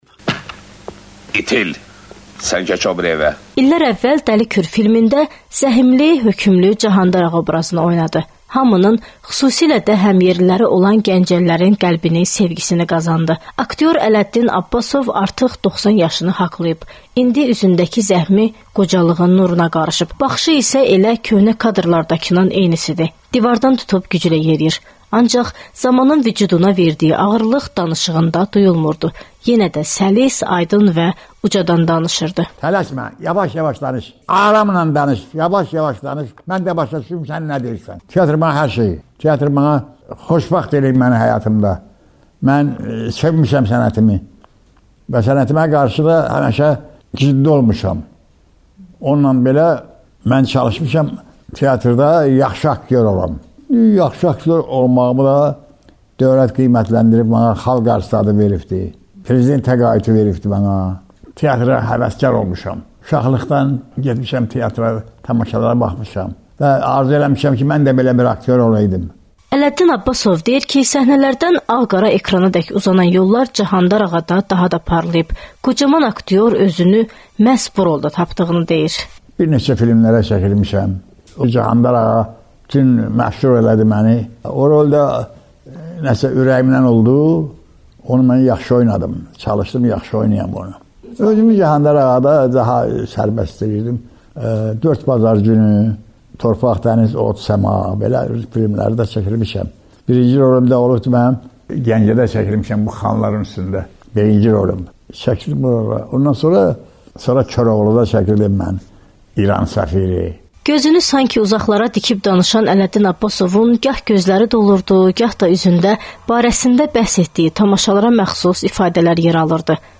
Azərbaycan kinosunun 90 yaşlı Cahandar Ağası ilə söhbət...